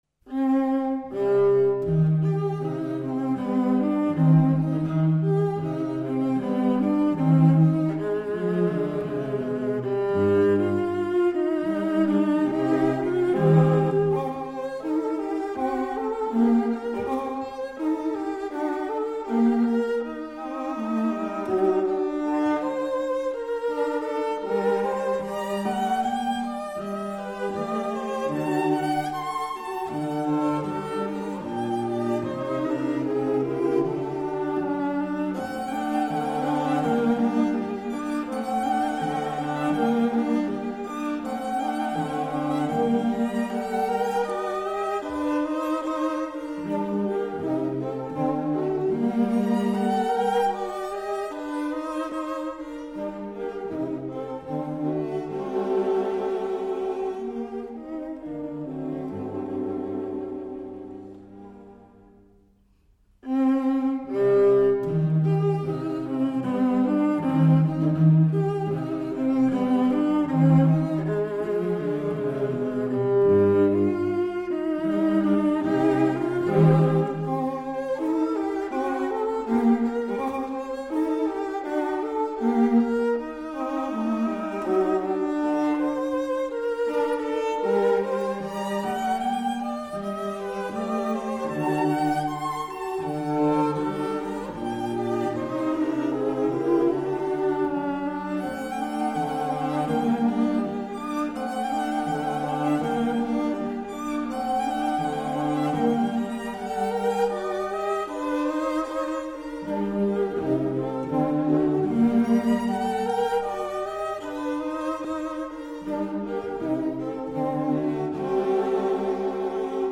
String Quartet in E flat major
Andante